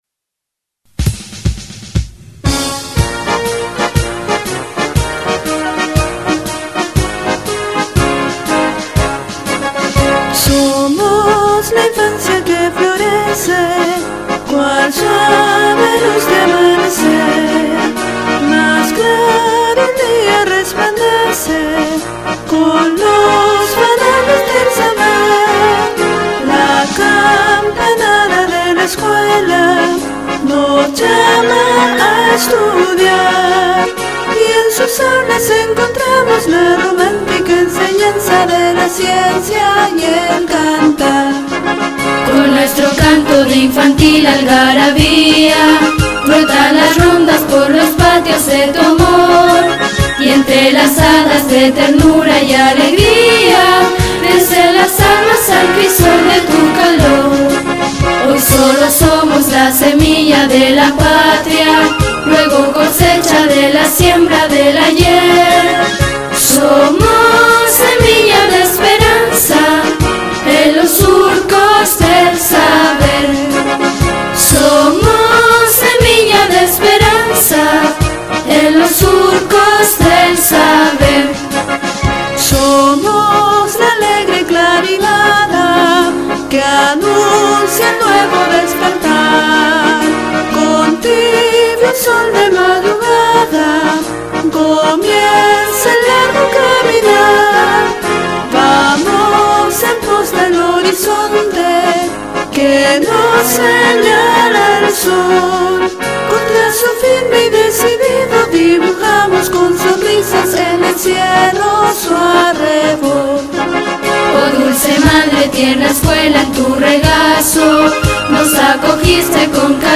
Himno de la Escuela